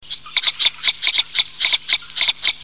Canard souchet, femelle
souchet.mp3